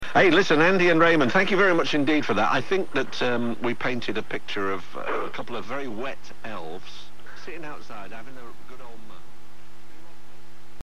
7) A demonstration of a broadcast station being "faded out" using the antenna coupler as an R.F. attenuator.
Ant-fadeout.mp3